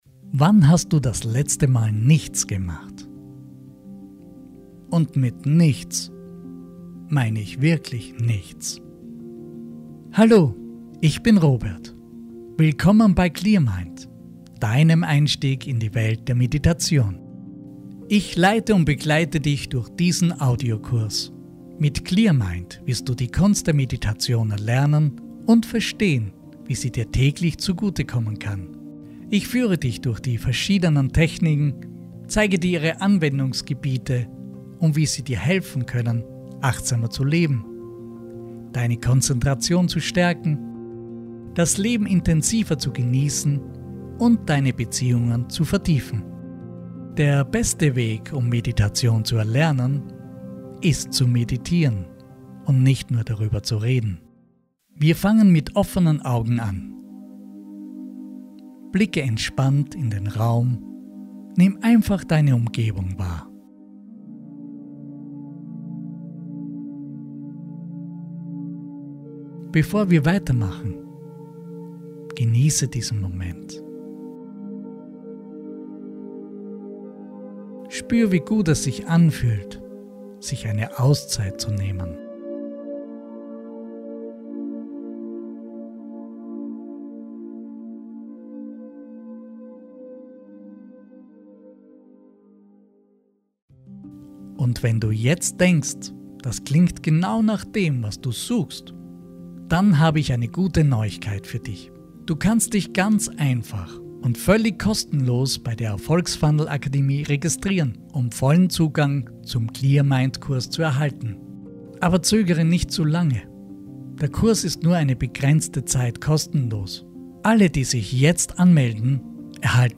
✅ Geführte Meditationen für Anfänger & Erfahrene